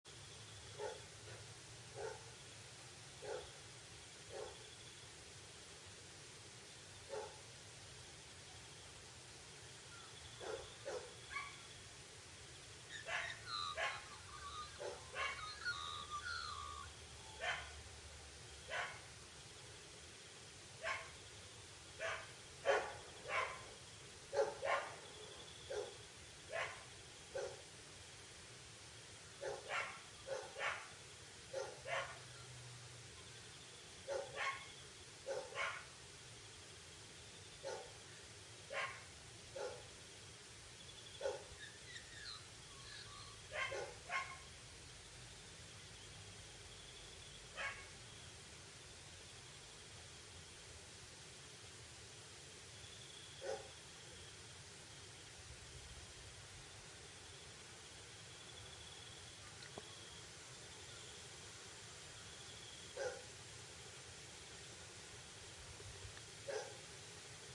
Dogs Bark Back Efecto de Sonido Descargar
Dogs Bark Back Botón de Sonido